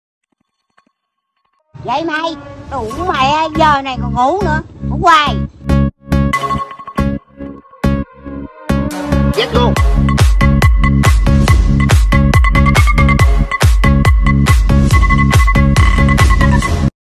Nhạc Chuông Báo Thức Bà Già Chửi